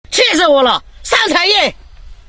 SFX气死我了上才艺音效下载
SFX音效